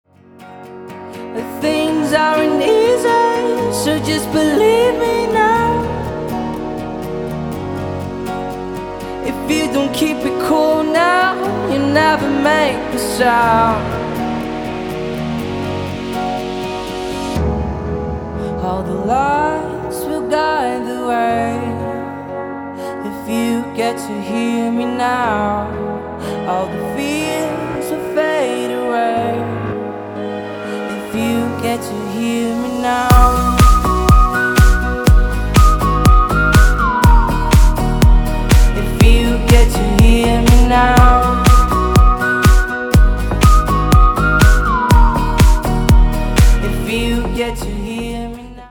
• Качество: 320, Stereo
свист
мужской голос
спокойные
house